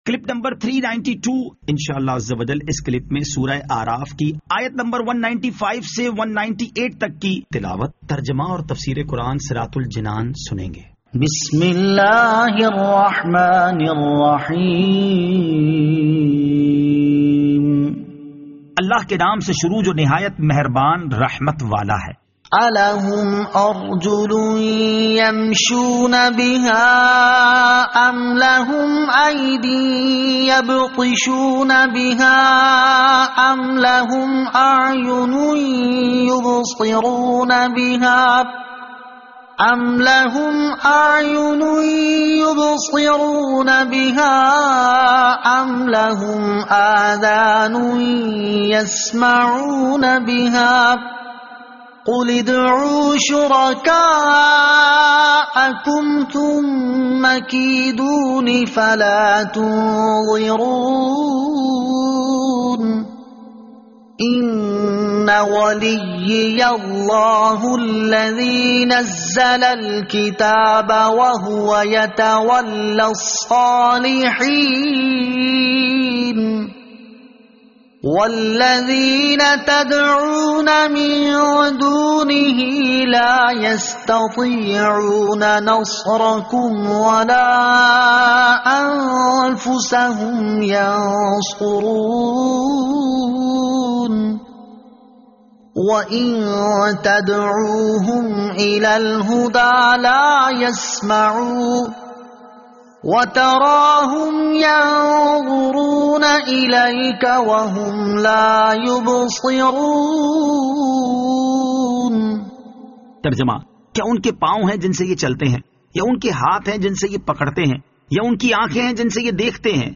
Surah Al-A'raf Ayat 195 To 198 Tilawat , Tarjama , Tafseer